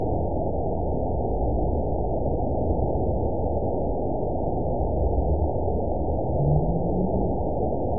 event 920402 date 03/23/24 time 10:56:59 GMT (1 year, 1 month ago) score 7.09 location TSS-AB02 detected by nrw target species NRW annotations +NRW Spectrogram: Frequency (kHz) vs. Time (s) audio not available .wav